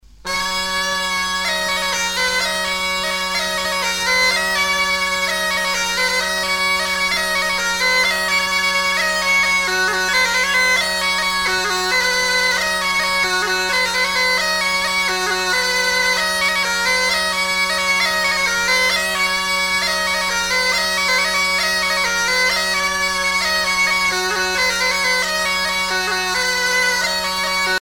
danse : ronde
Pièce musicale éditée